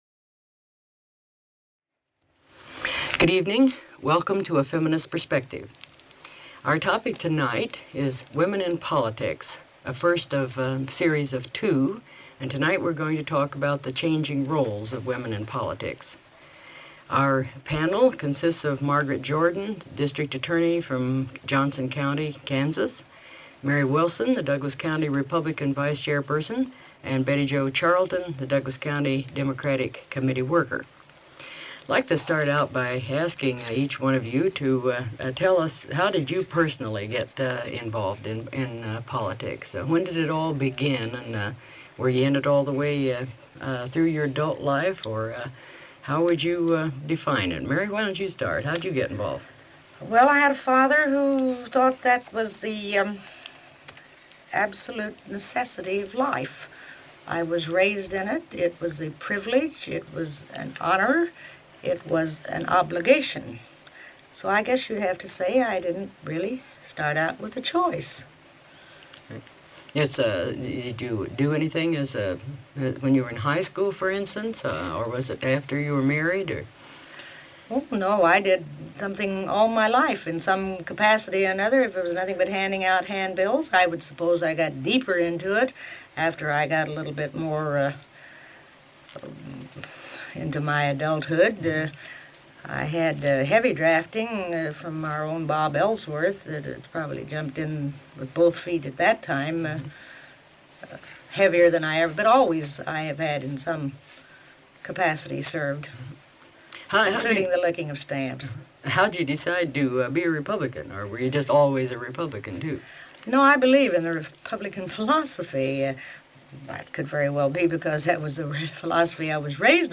Feminist Perspective radio program
Radio talk shows